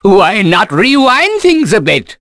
Oddy-Vox_Skill2.wav